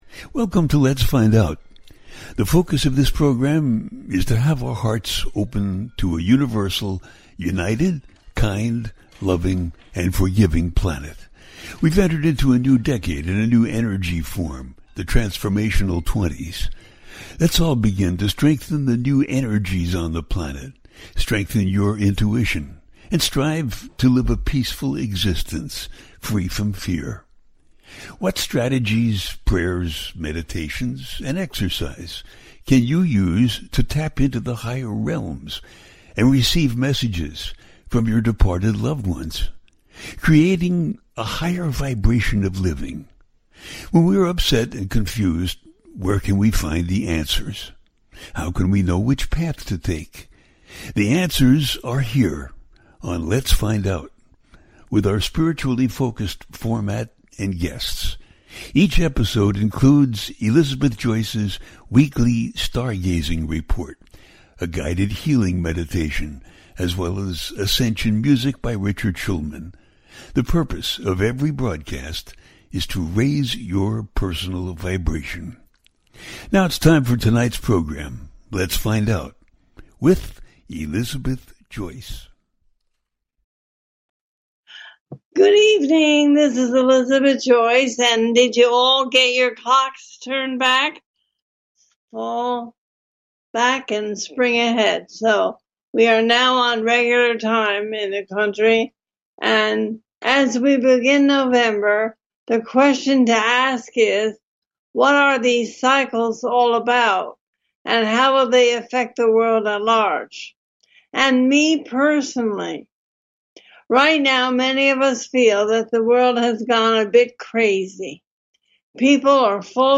A Teaching Show
Let’s Find Out brings to its listeners illuminating and enthralling exploration of the connection between our minds and our bodies. This show brings a series of fascinating interviews with experts in the field of metaphysics.
The listener can call in to ask a question on the air.
Each show ends with a guided meditation.